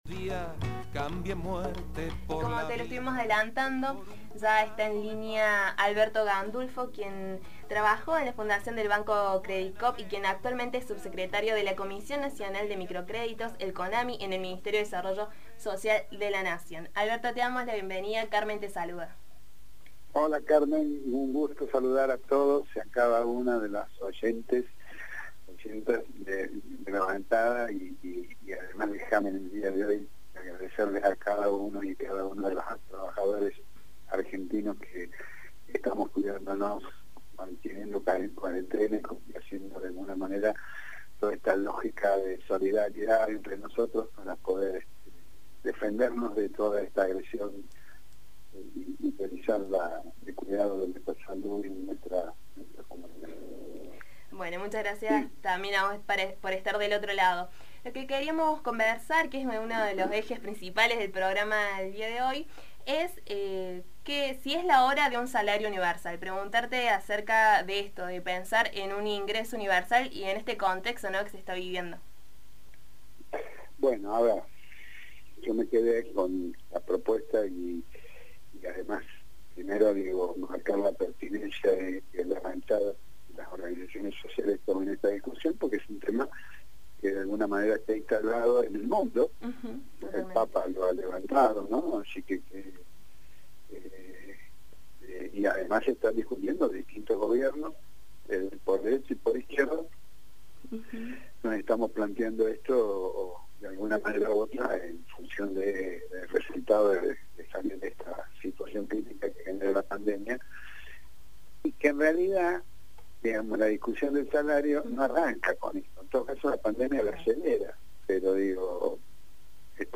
Nos comunicamos con Alberto Gandulfo, subsecretario de la comisión nacional de microcréditos (CONAMI), del Ministerio de Desarrollo Social de la Nación.